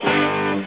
Hit.snd